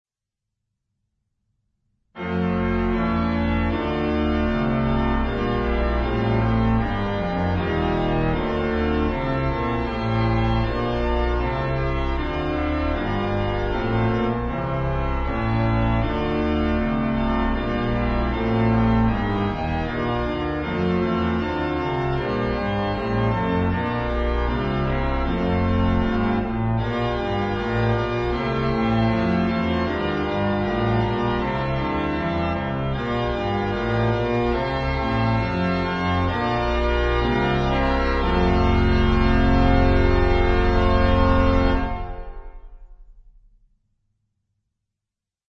The stately Christmas hymn tune
has been re-harmonized
Hymn Harmonizations